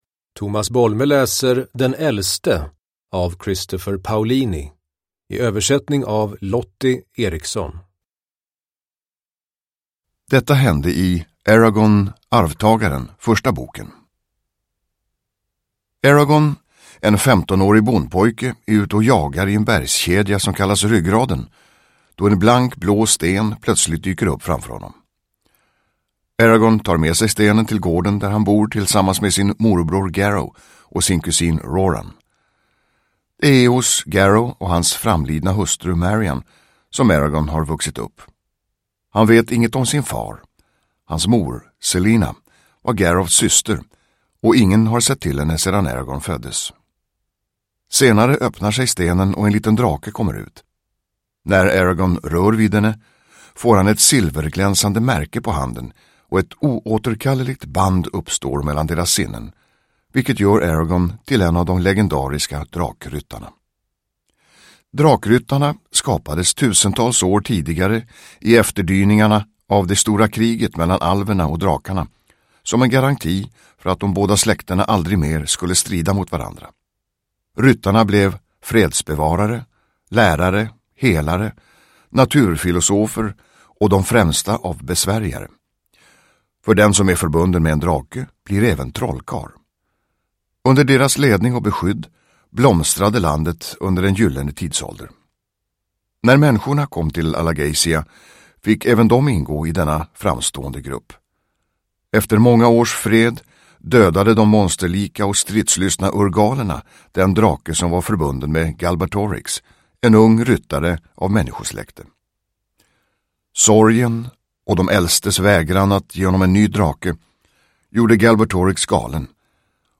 Den äldste – Ljudbok
Uppläsare: Tomas Bolme